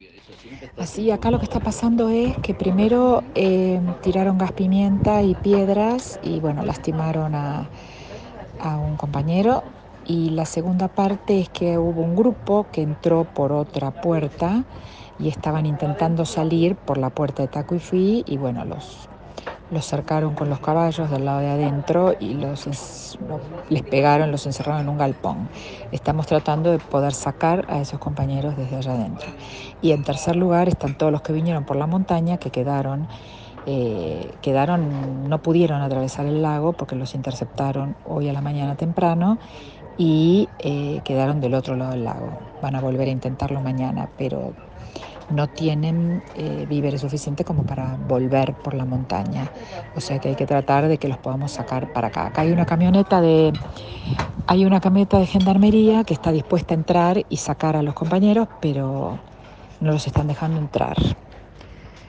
Liliana Mazure reporta el estado de situación tras la agresión de los peones del pirata inglés.